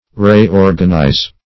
\Re-or"gan*ize\(r[-e]*[^o]r"gan*[imac]z), v. t. & i.